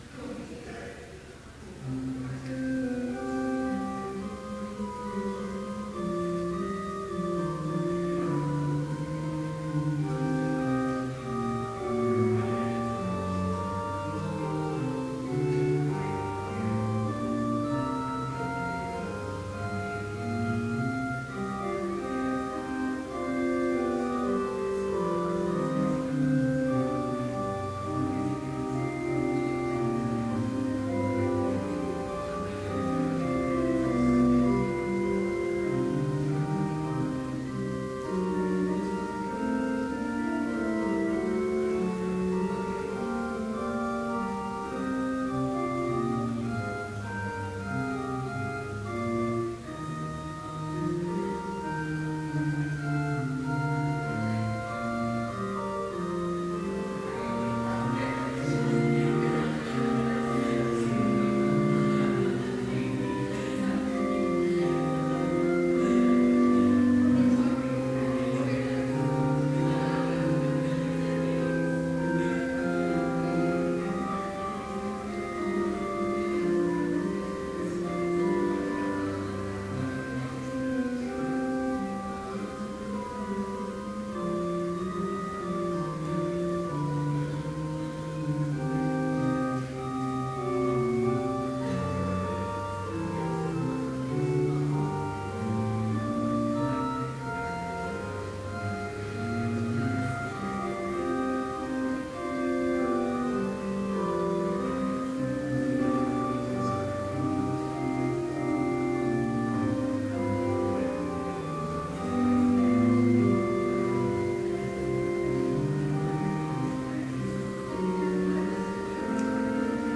Complete service audio for Chapel - November 15, 2012